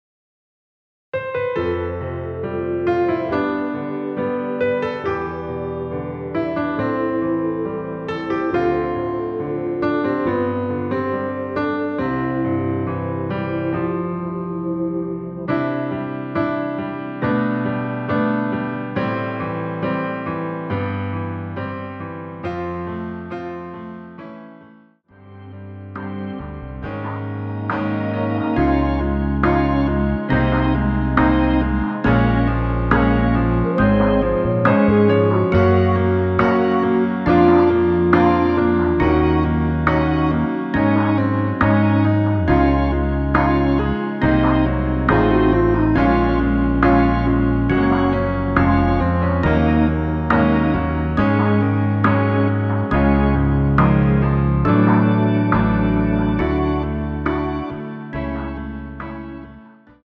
원키에서(-2)내린 MR입니다.
앞부분30초, 뒷부분30초씩 편집해서 올려 드리고 있습니다.
곡명 옆 (-1)은 반음 내림, (+1)은 반음 올림 입니다.